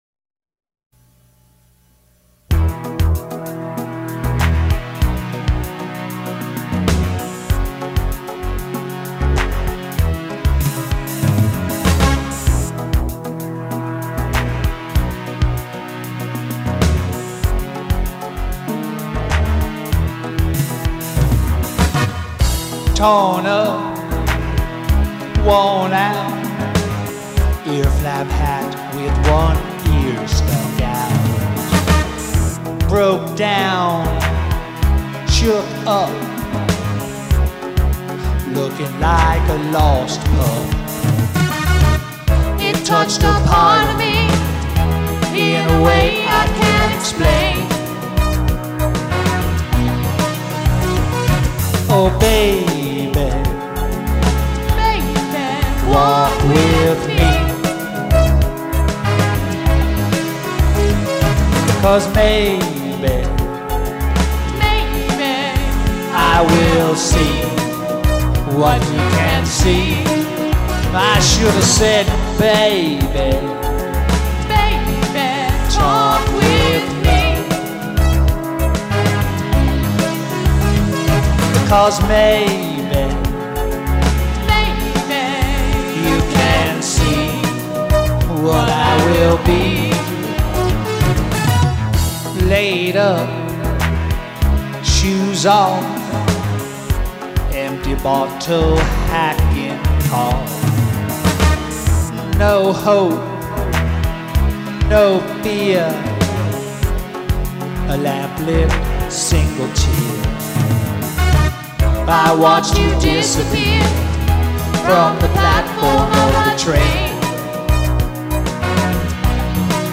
Steel Drums, Musette, Hand Claps, Dobro & Tubular Bells
Vocals; Guitar;
Synthesizer